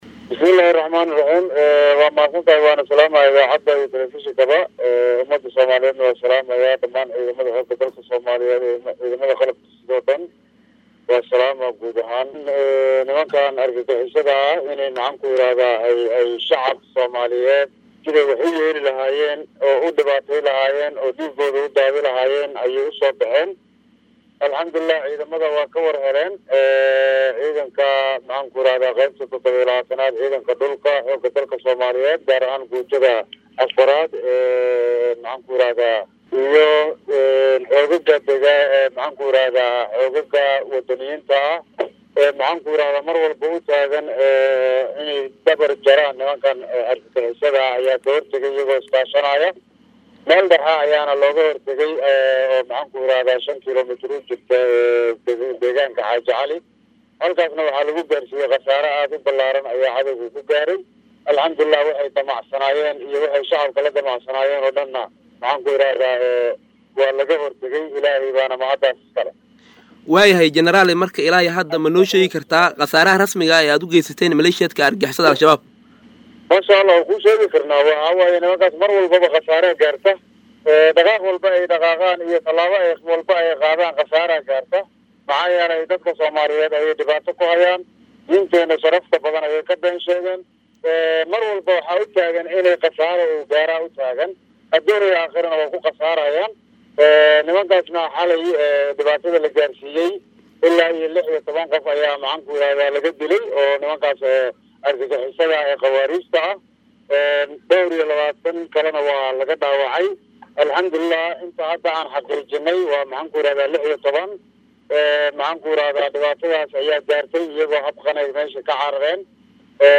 Taliyaha qeybta 27-aad ee ciidanka Xoogga dalka, Janaraal Axmed Maxamed Maxamuud (Tareedisho) oo wareysi gaar ah siiyay Radio Muqdisho ayaa sheegay in ay dileen 16 ka tirsanaa Al-shabaab ku dhawaad 30 kalane ay dhaawaceen kuwaas oo iskugu jiray horjoogeyaal iyo maleeshiyaad, kaddib markii ay ciidamadu ka hortageeen weerar argagaxisada ay dooneysay in ay ku dhibaateyso shacabka Soomaaliyeed.